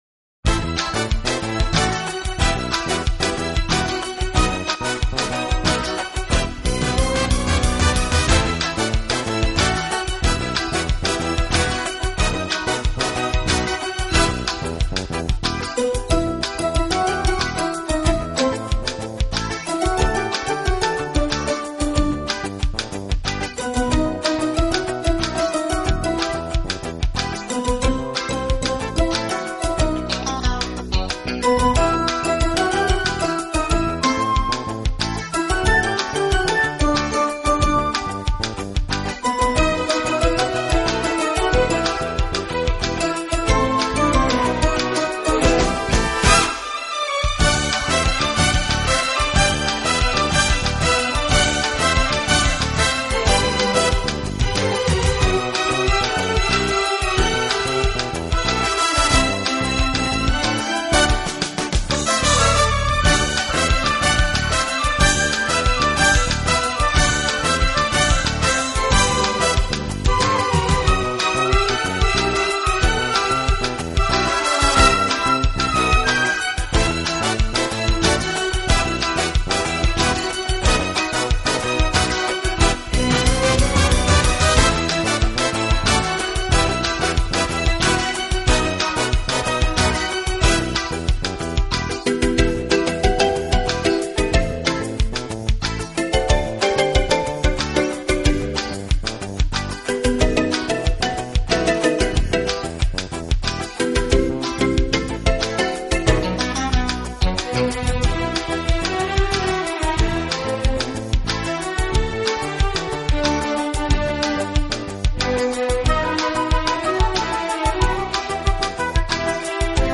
“清新华丽，浪漫迷人”